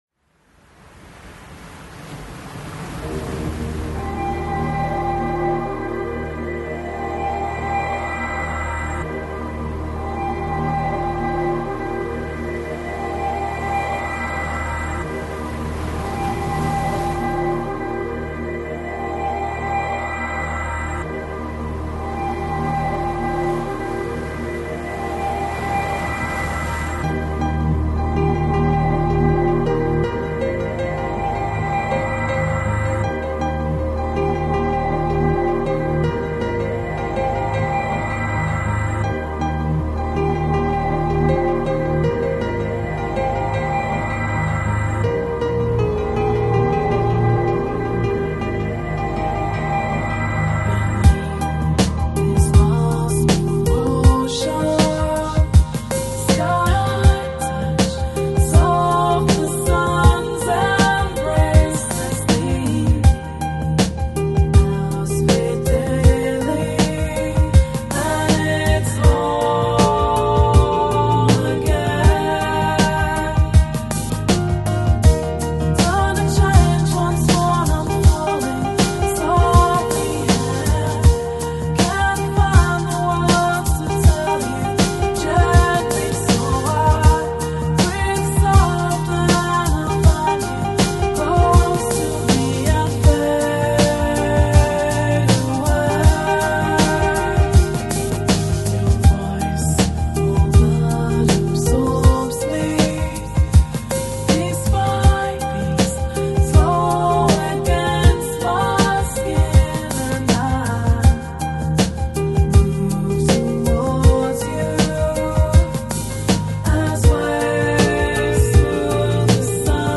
Chill Out, Lounge, Downtempo Год издания